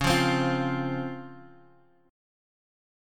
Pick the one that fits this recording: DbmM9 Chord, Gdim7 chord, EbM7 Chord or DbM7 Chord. DbM7 Chord